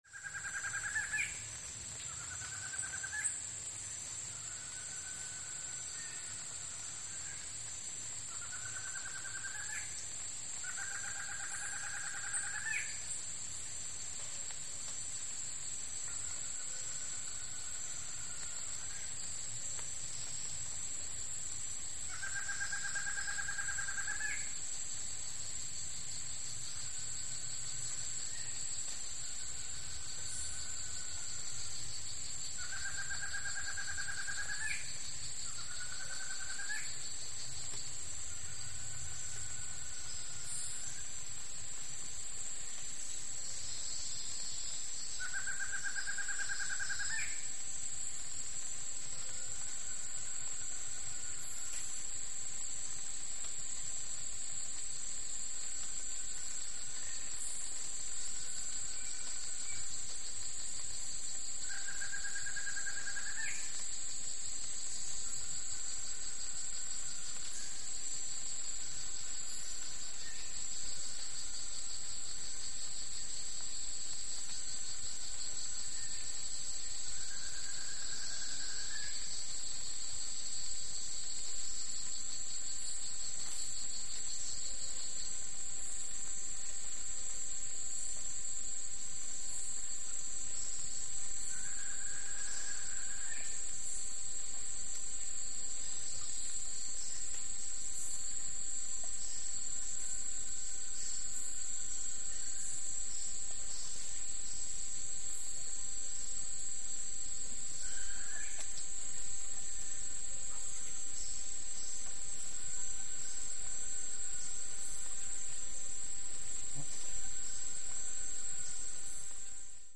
Exotic Bird Calls With Insects And Medium Rain On Foliage.